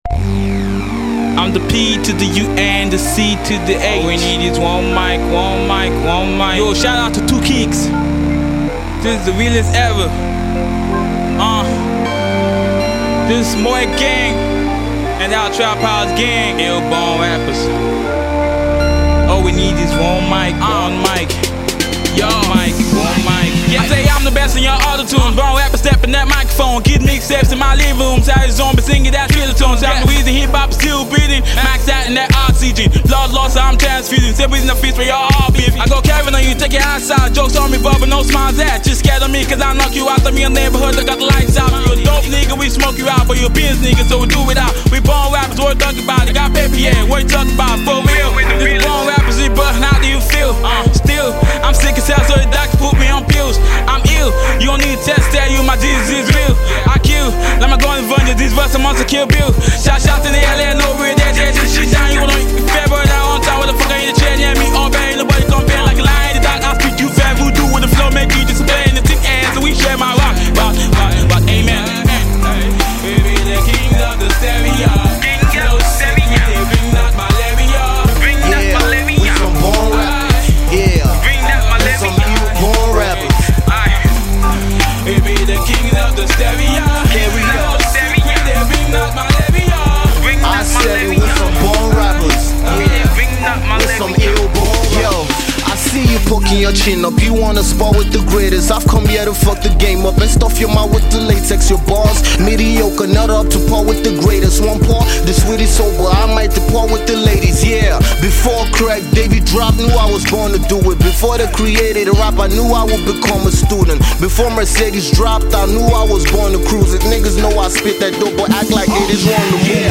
a straight up traditional rap cut